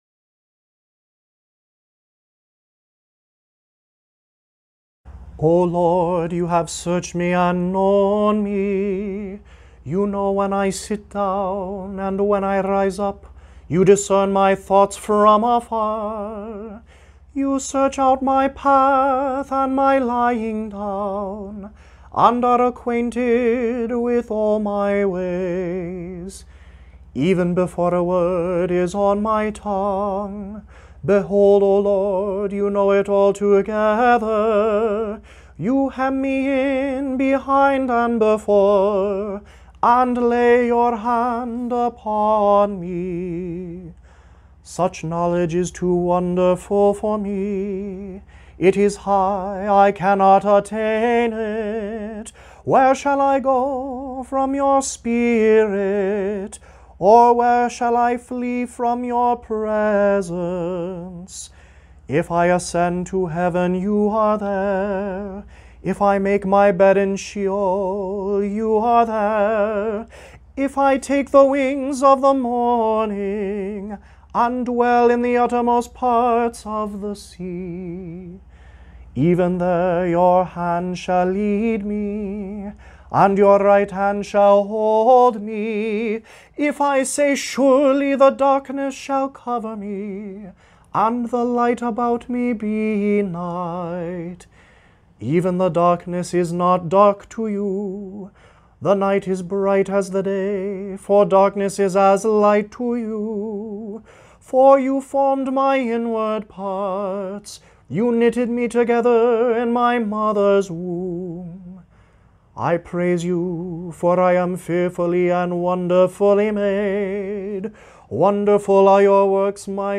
Psalm 139 (LSB Tone F: